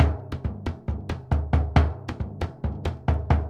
Bombo_Merengue 136_1.wav